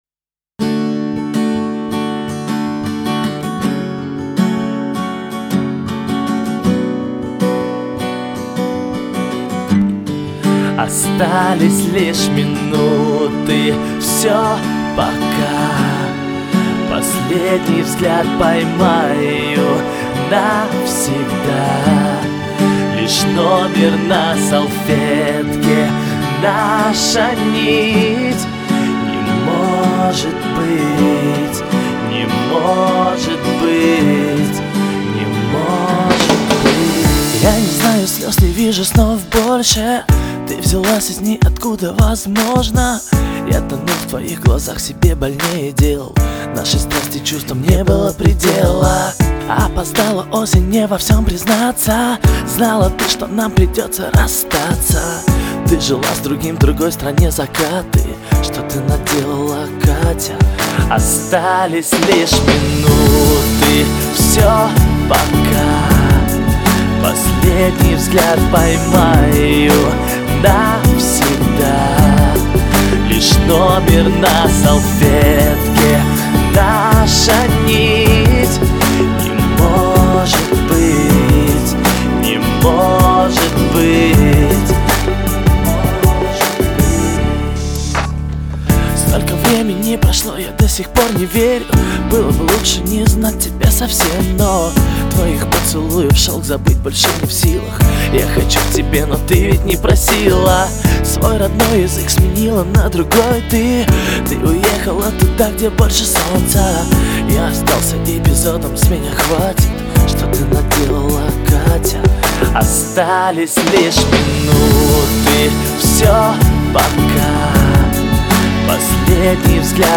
Genre : Blues
Channel(s) : 2 channels